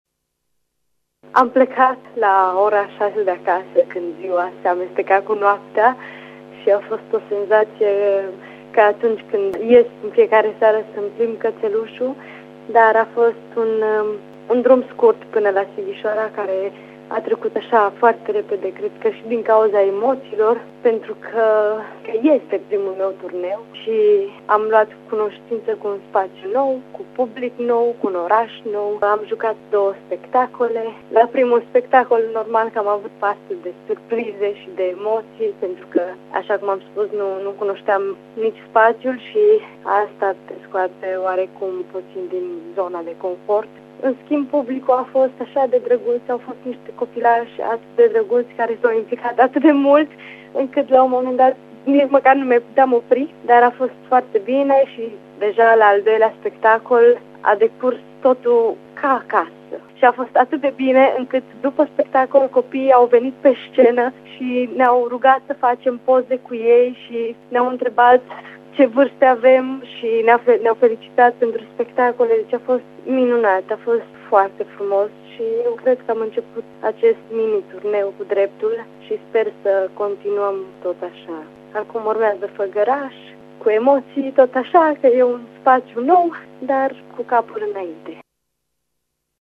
În jurnalul ei, deocamdată doar un jurnal sonor şi virtual, a notat prima“escală”: 9 martie- Sighişoara.